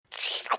Download Comic Lick sound effect for free.
Comic Lick